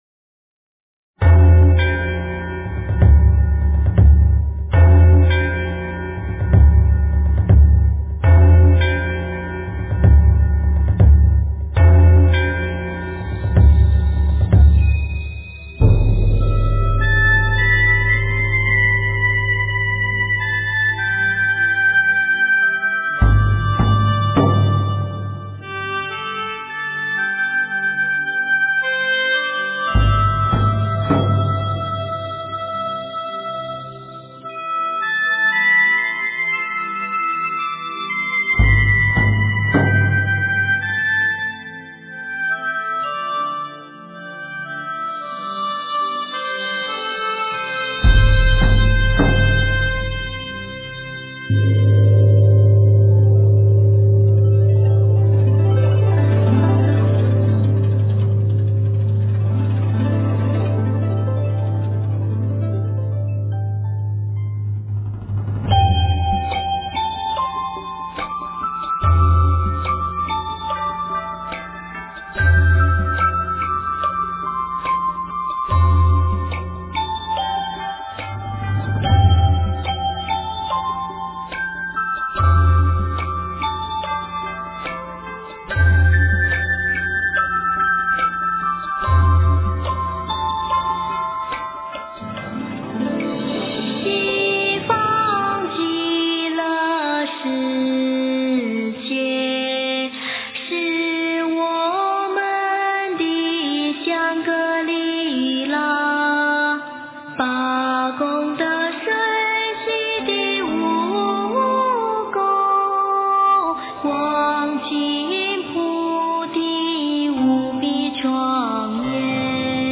诵经
佛音 诵经 佛教音乐 返回列表 上一篇： 大悲咒 下一篇： 心经 相关文章 Saffron Morning--Dan Gibson-禅修音乐 Saffron Morning--Dan Gibson-禅修音乐...